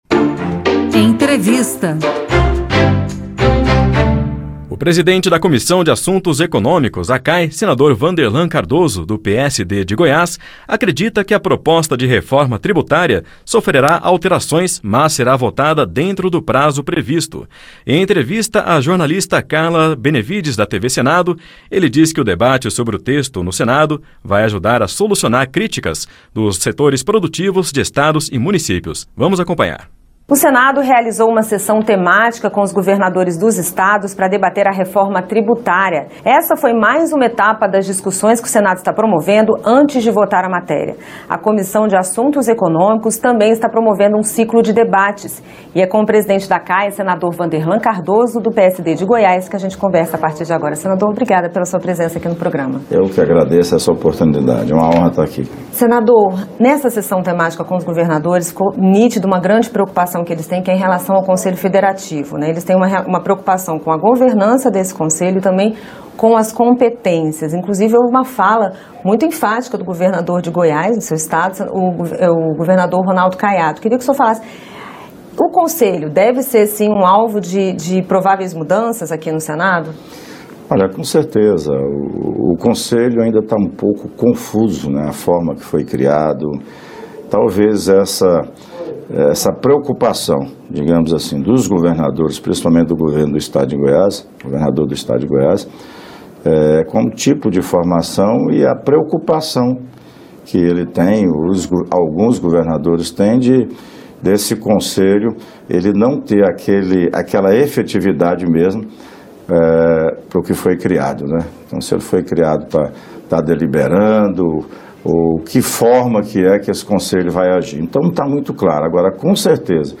Programa jornalístico que trata da agenda do Congresso Nacional, com entrevistas, comentários e reportagens sobre os principais assuntos em debate e em votação.